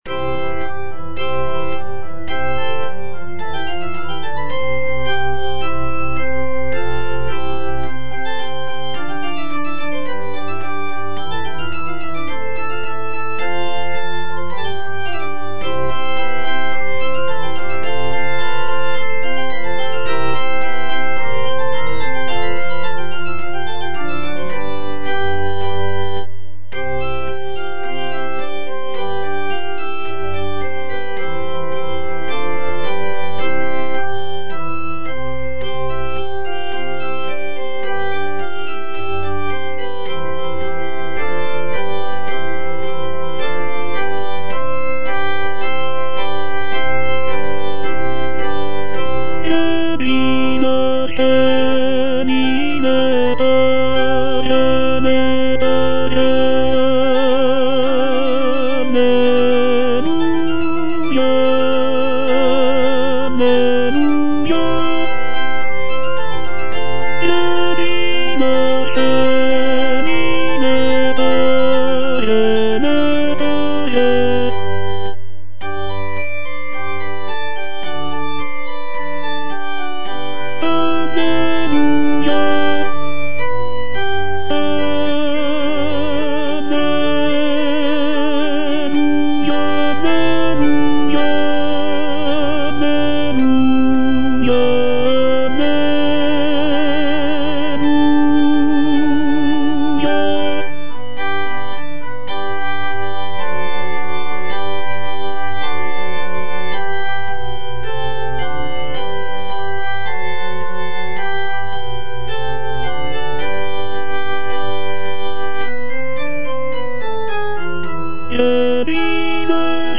Tenor (Voice with metronome) Ads stop